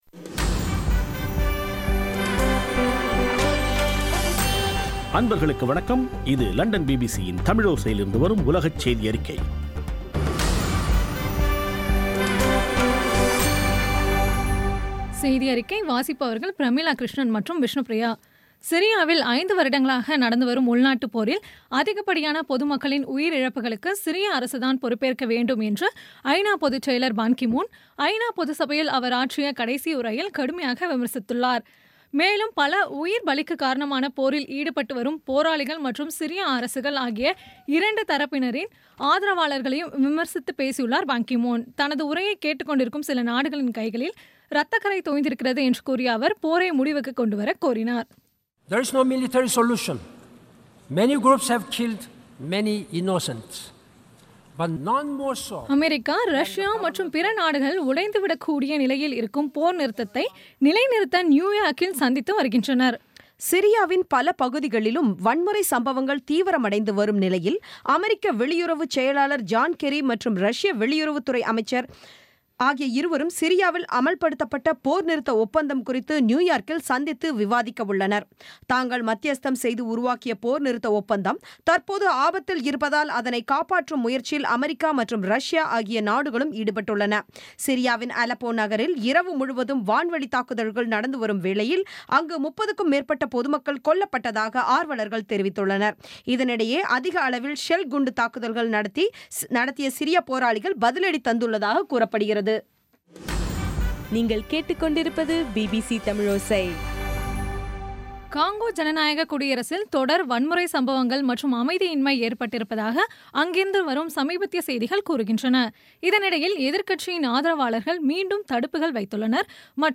இன்றைய (ஆகஸ்ட் 20ம் தேதி ) பிபிசி தமிழோசை செய்தியறிக்கை